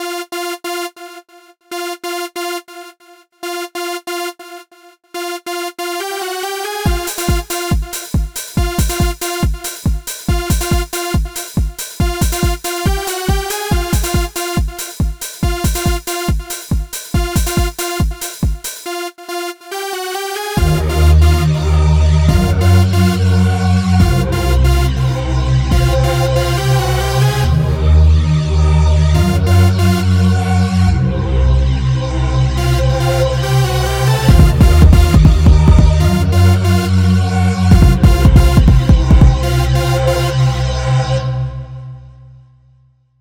techno1.ogg